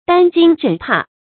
擔驚忍怕 注音： ㄉㄢ ㄐㄧㄥ ㄖㄣˇ ㄆㄚˋ 讀音讀法： 意思解釋： 擔心害怕。